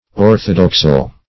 Search Result for " orthodoxal" : The Collaborative International Dictionary of English v.0.48: Orthodoxal \Or"tho*dox`al\ ([^o]r"th[-o]*d[o^]ks`al), a. Pertaining to, or evincing, orthodoxy; orthodox.
orthodoxal.mp3